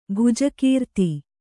♪ bhuja kīrti